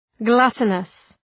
Προφορά
{‘glʌtənəs}